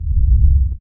fbrawl_rock_rising.ogg